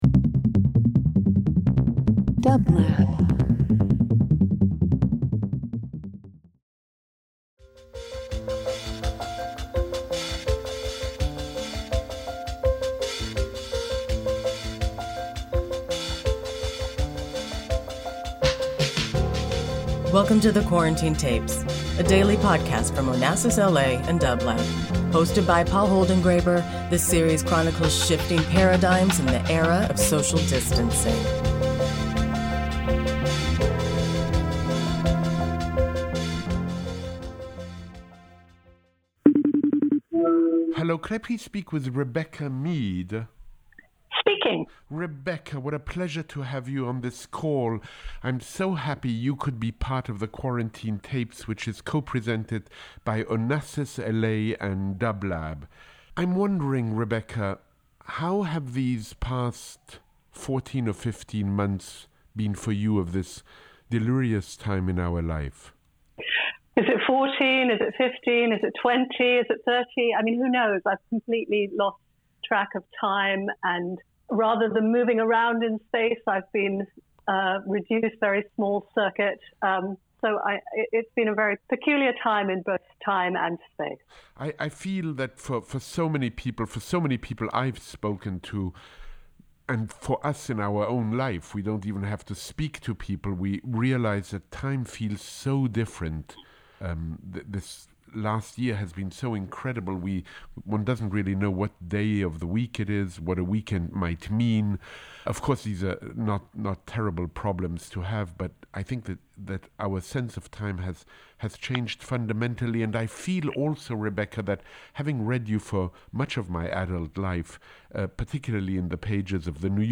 Each day, Paul calls a guest for a brief discussion about how they are experiencing the global pandemic.
Paul Holdengräber is joined by writer Rebecca Mead on episode 199 of The Quarantine Tapes. A longtime contributor to The New Yorker, Rebecca talks with Paul about writing and the changing experience of time under quarantine.
Paul Holdengräber Rebecca Mead The Quarantine Tapes 07.08.21 Interview Talk Show The Quarantine Tapes : A week-day program from Onassis LA and dublab.